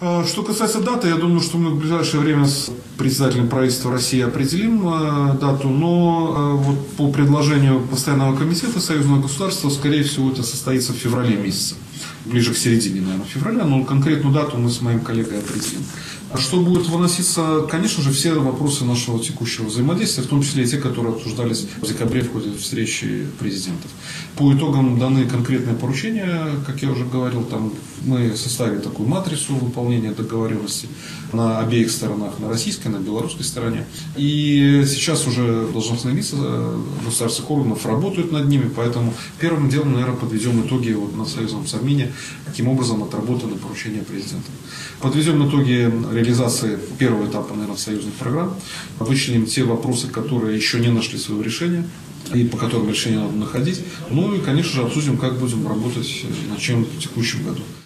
Очередное заседание Совета Министров Союзного государства может состояться в феврале, сообщил журналистам во время посещения Барановичей премьер-министр Роман Головченко.